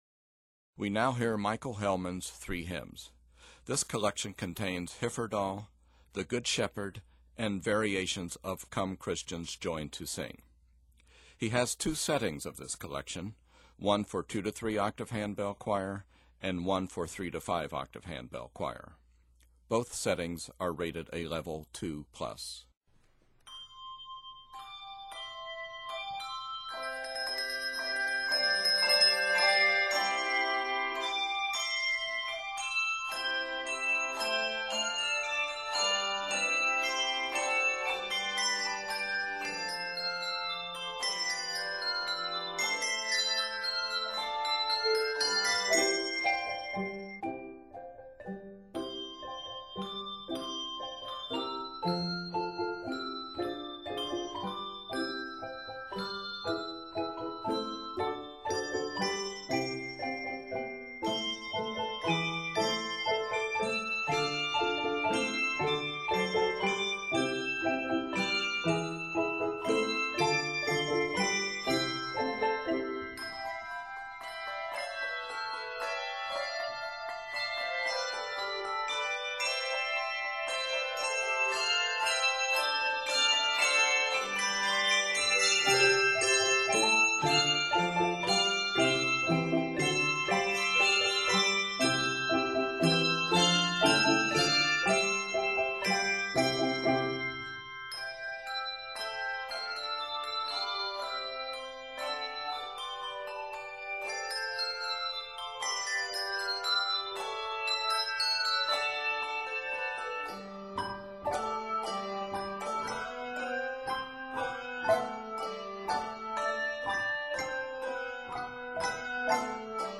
Various special techniques are scored to add interest.
Octaves: 2-3